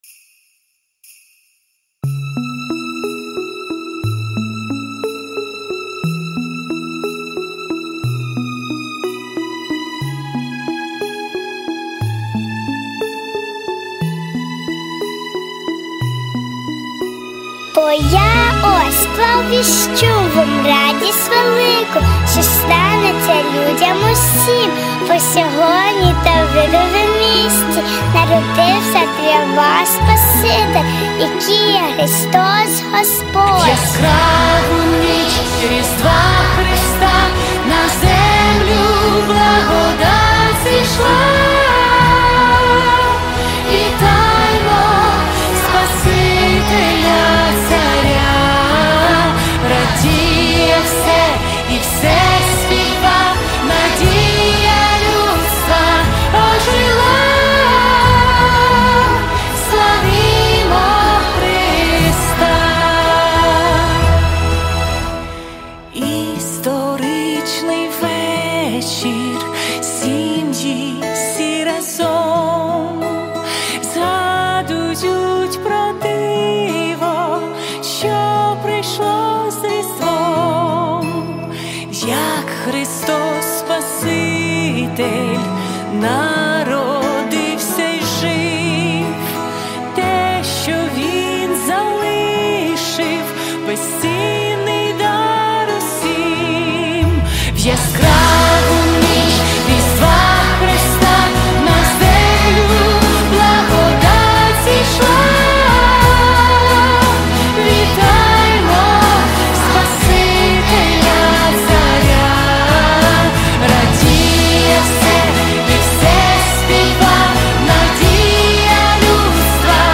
4430 просмотров 2320 прослушиваний 485 скачиваний BPM: 176